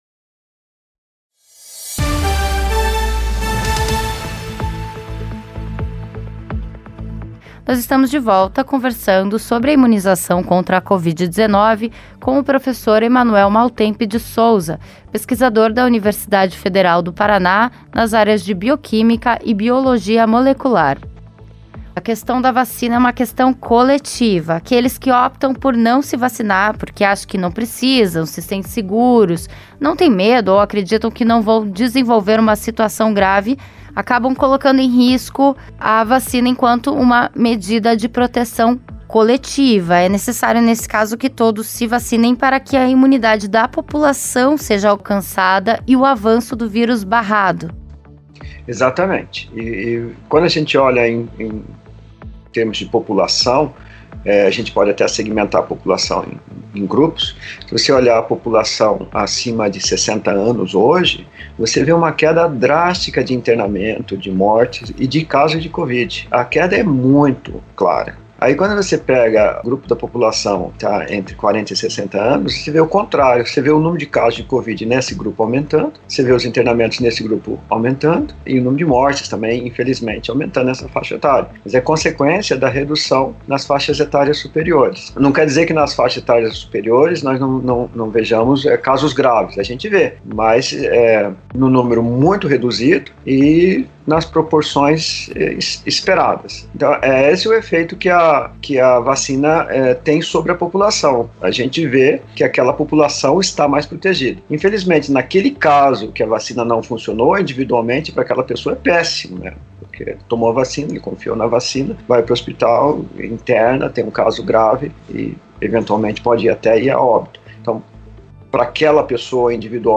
ENTREVISTA: A importância da segunda dose e da vacinação em massa
2906-ENTREVISTA-VACINAS-PARTE2.mp3